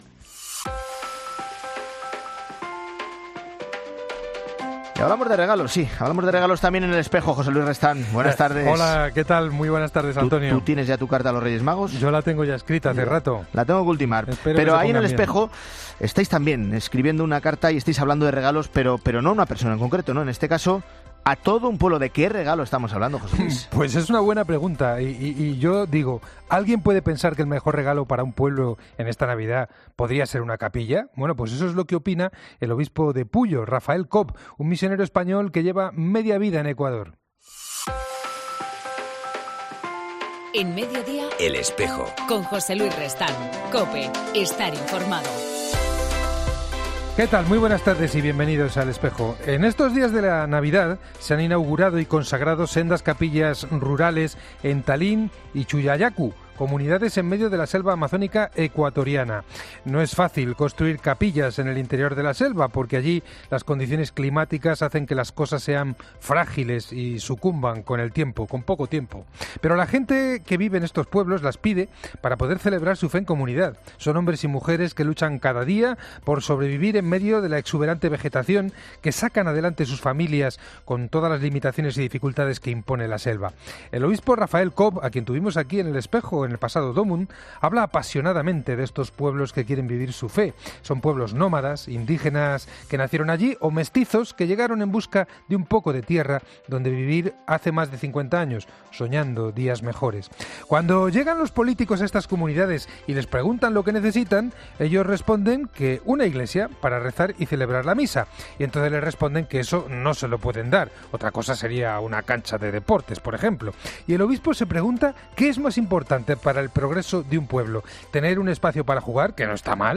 AUDIO: En El Espejo del 28 de diciembre hablamos de la figura del jesuita Tiburcio Arnaiz, fundador de las Misioneras de las Doctrinas Rurales y en...